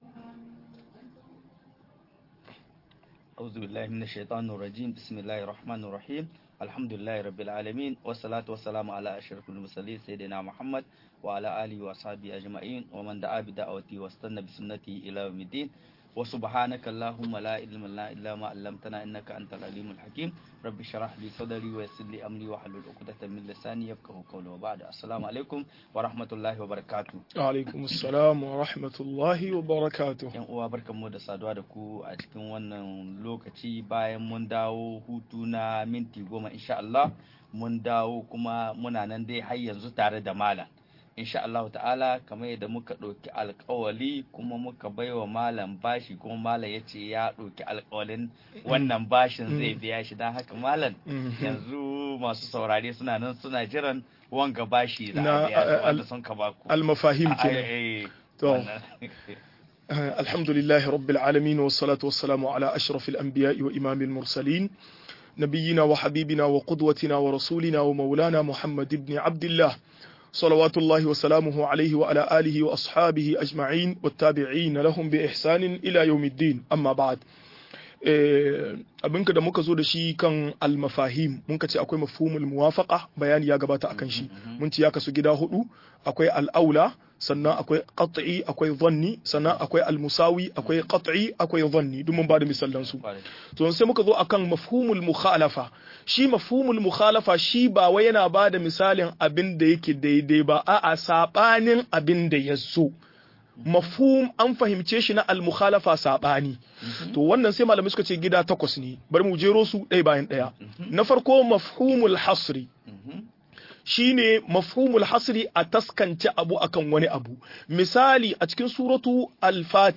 Mazhabobin usulul fiqh - MUHADARA